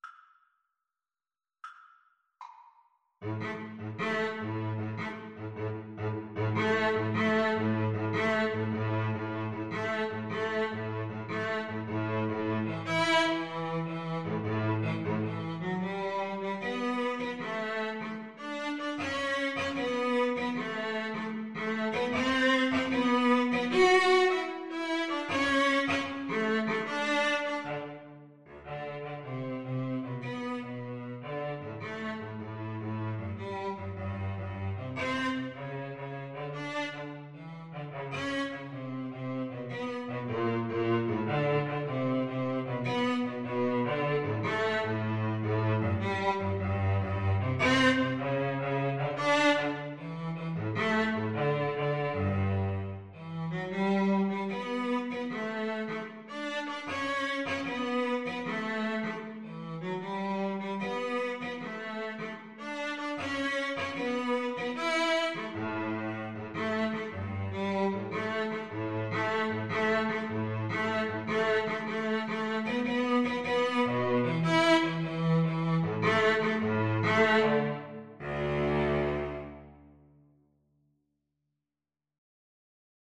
Classical (View more Classical Violin-Cello Duet Music)